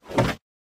creatura_hit_2.ogg